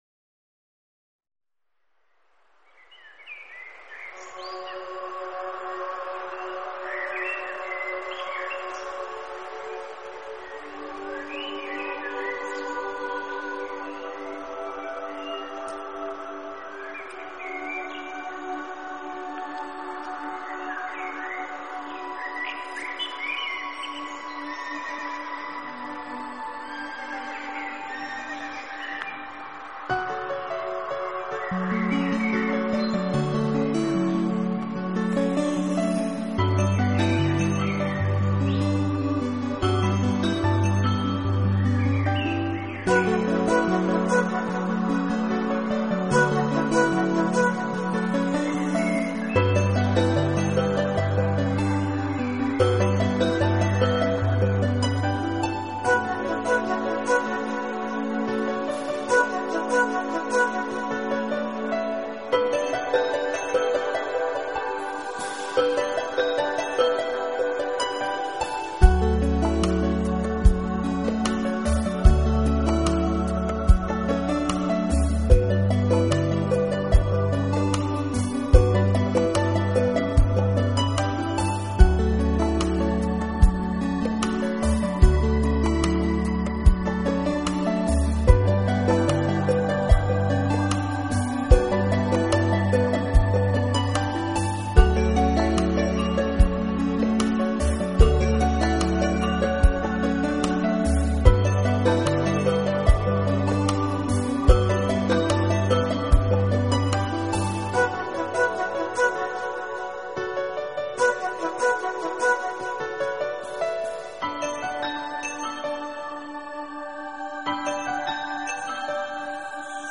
钢琴、长笛、竖琴、弦声与大自然制作了这个神奇而生动的梦。